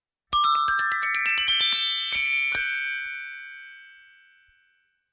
Glocken_link1.mp3